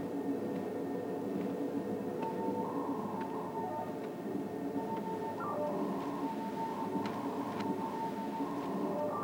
operationscenter.wav